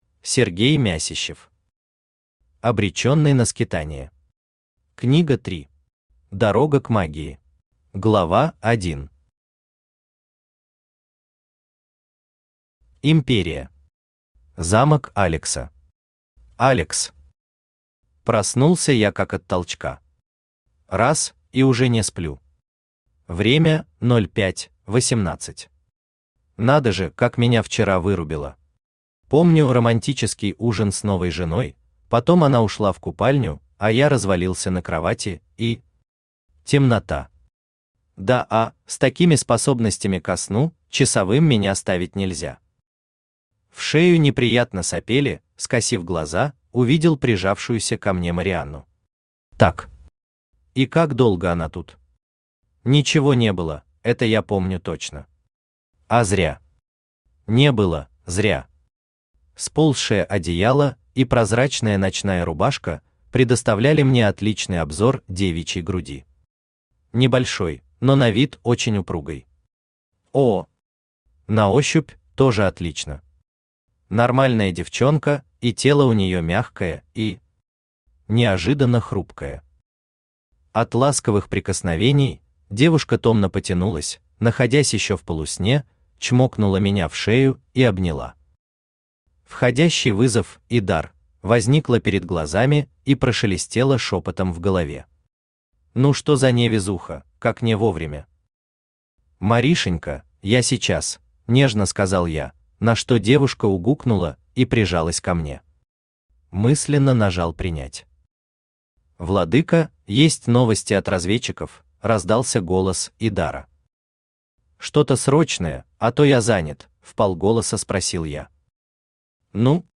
Аудиокнига Обреченный на скитания. Книга 3. Дорога к магии | Библиотека аудиокниг
Дорога к магии Автор Сергей Мясищев Читает аудиокнигу Авточтец ЛитРес.